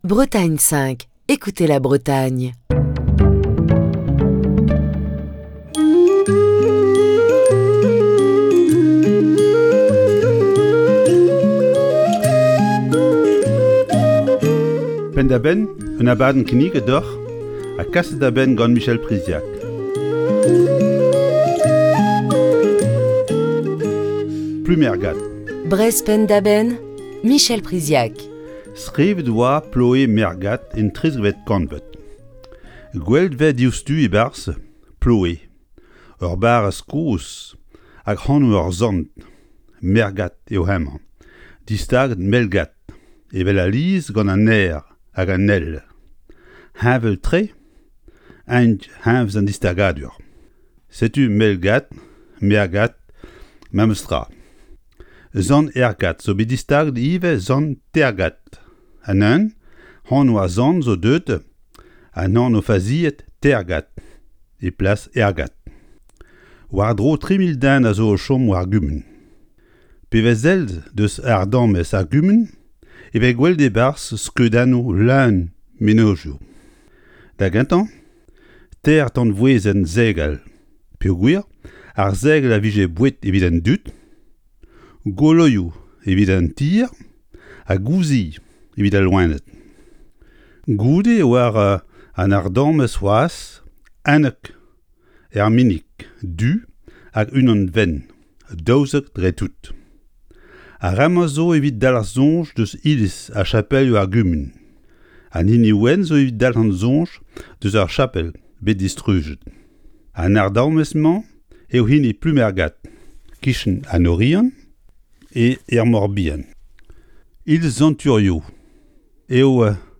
Chronique du 9 mars 2022.